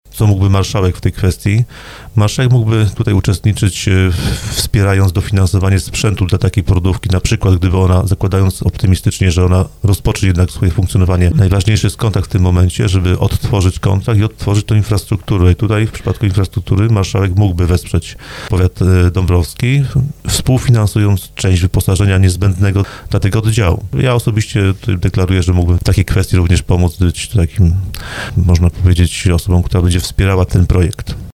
Mówił o tym w audycji Słowo za Słowo Wiceprzewodniczący Sejmiku Województwa Małopolskiego Wojciech Skruch.